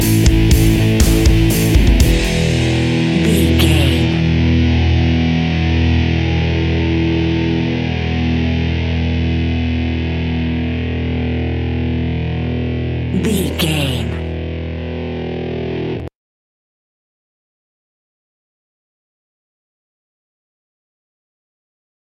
Aggressive Rock Metal Music Stinger.
Epic / Action
Ionian/Major
hard rock
heavy metal
distortion
Rock Bass
heavy drums
distorted guitars
hammond organ